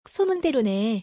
ソムンデロネ